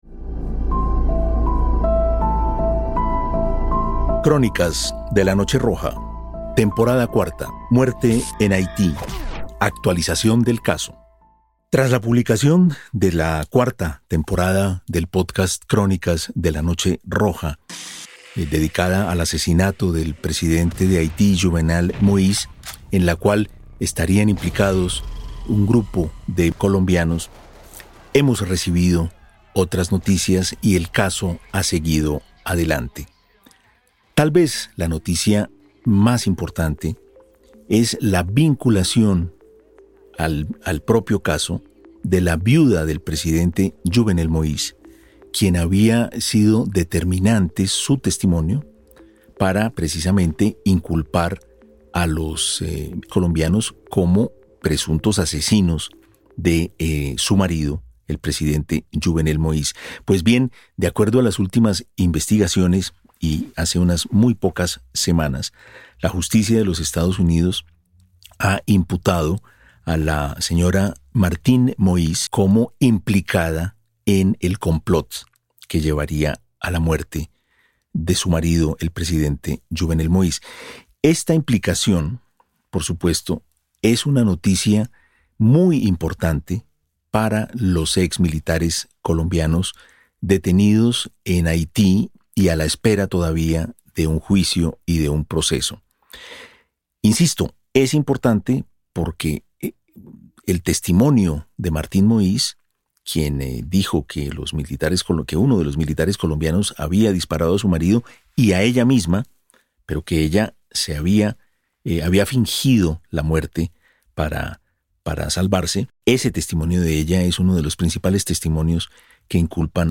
Una serie de conversaciones para conocer su versión de los hechos y la situación actual del caso.